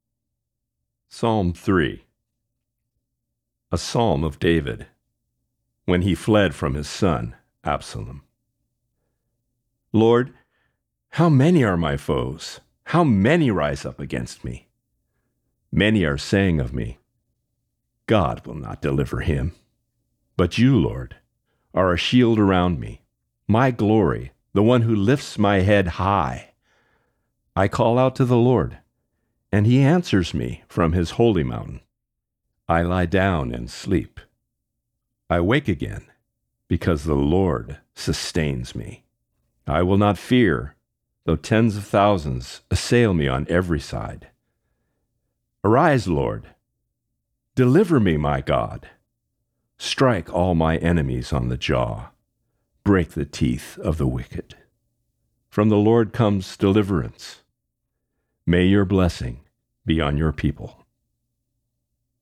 Reading: Psalm 3